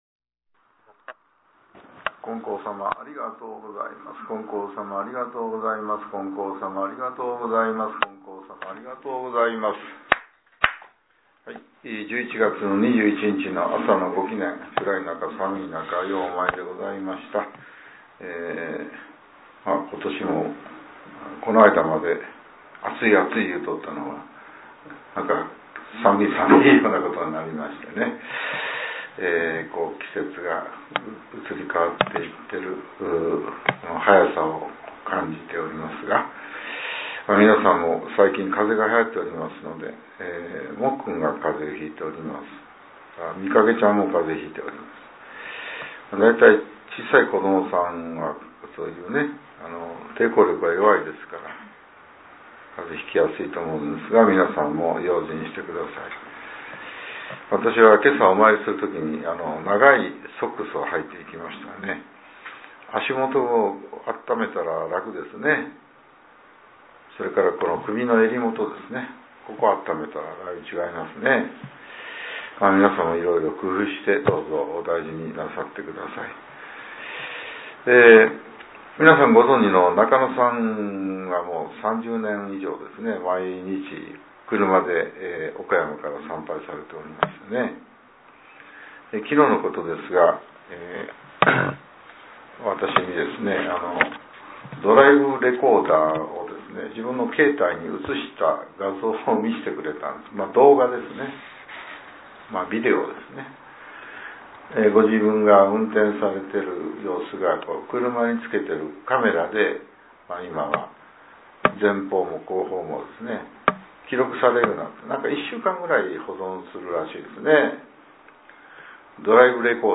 令和７年１１月２１日（朝）のお話が、音声ブログとして更新させれています。